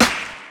CDK - BFM Snare.wav